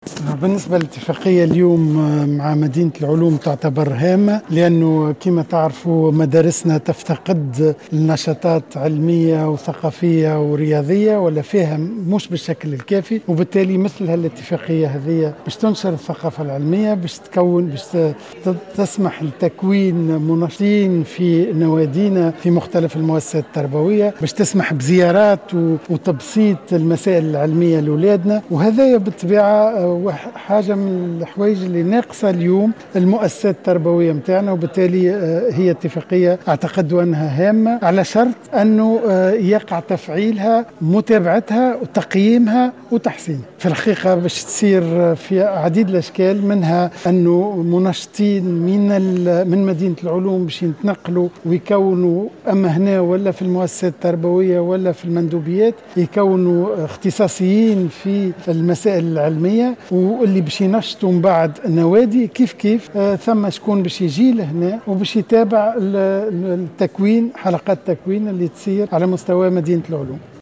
وتابع في تصريح لـ "الجوهرة أف أم" أن المدارس تفتقد لنشاطات ثقافية وعلمية ورياضية وليست بالشكل الكافي، وفق تعبيره.